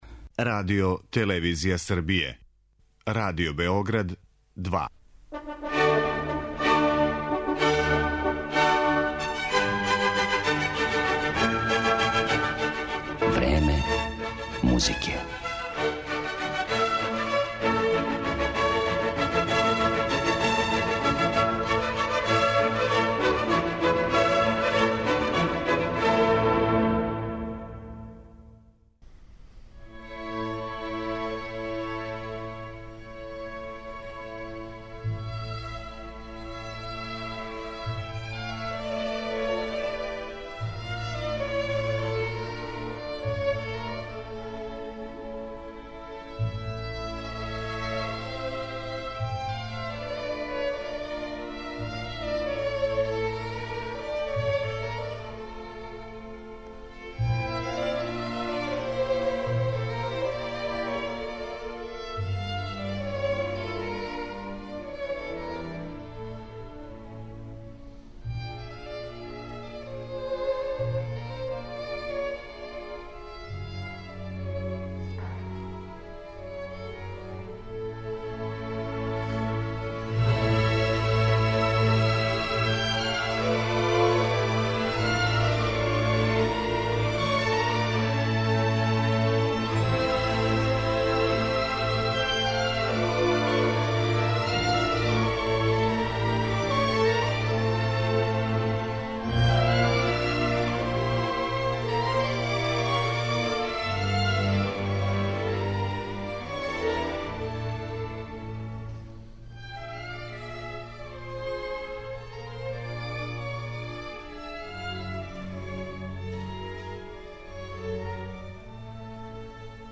Емисија класичне музике